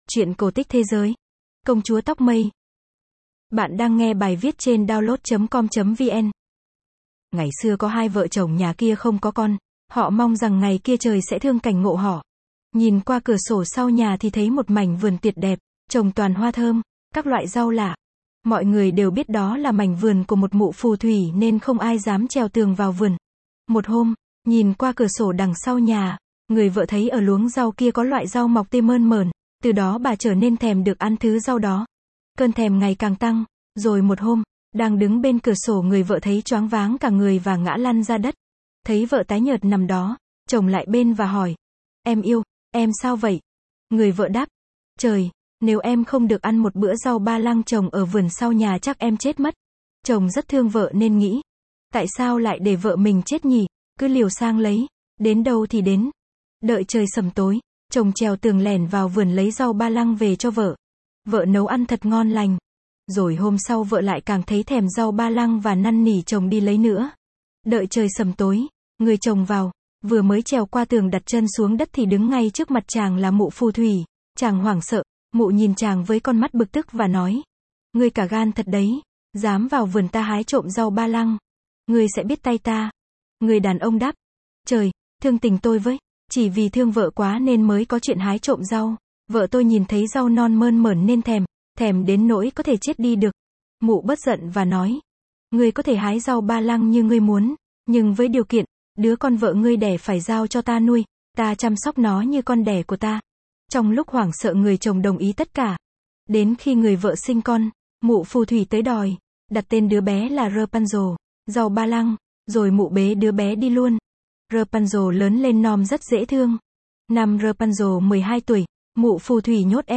Sách nói | Công chúa tóc mây